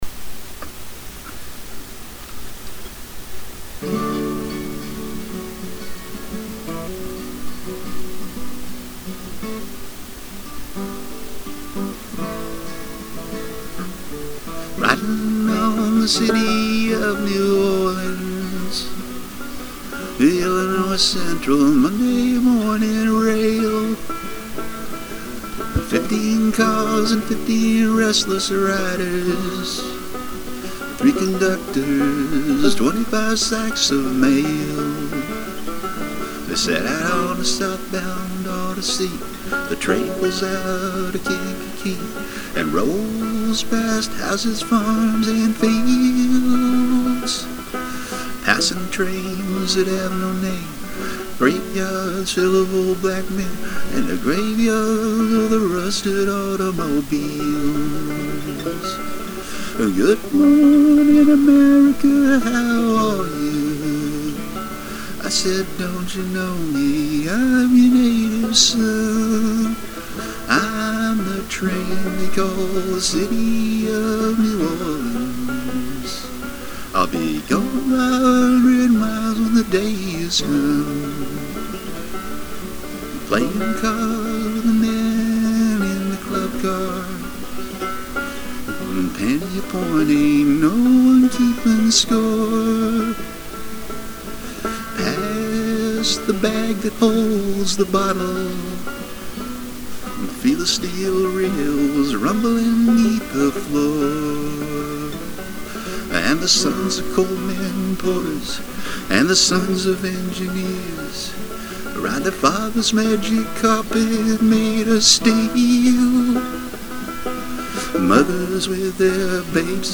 Vocals, 12 String Guitar,Banjo